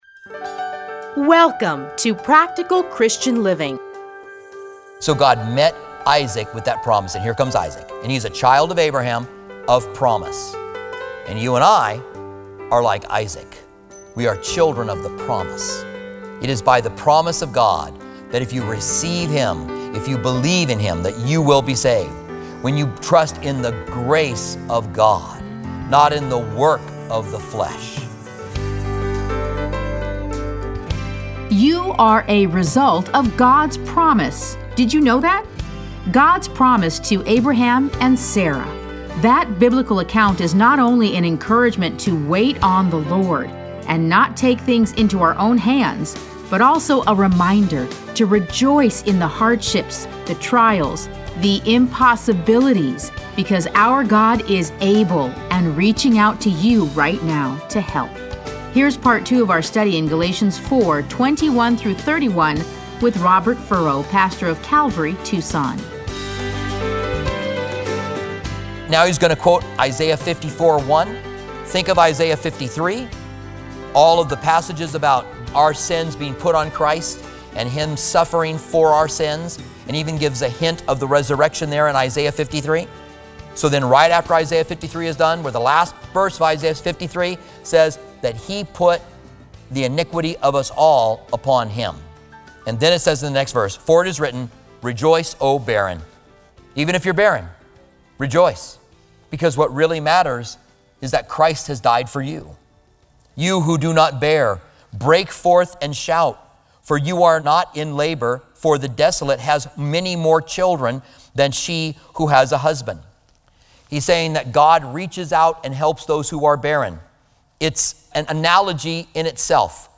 Listen to a teaching from Galatians 4:21-31.